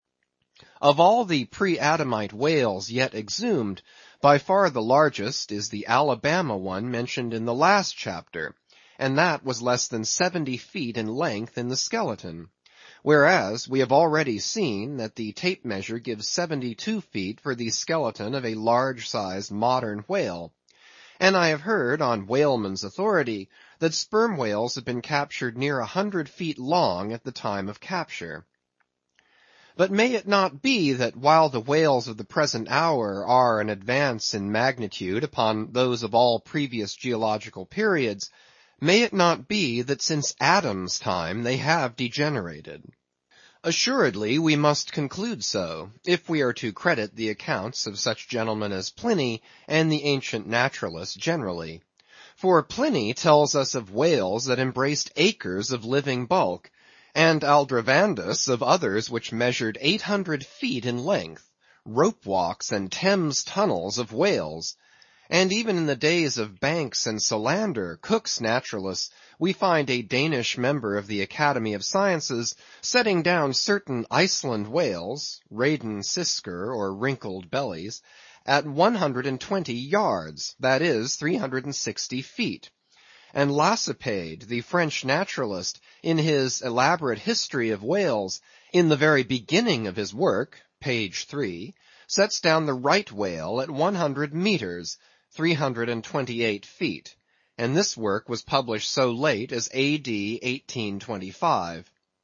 英语听书《白鲸记》第874期 听力文件下载—在线英语听力室